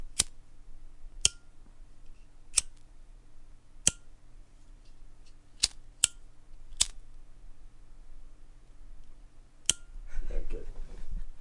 凉爽的声音 " 打火机的弹奏
描述：轻弹打火机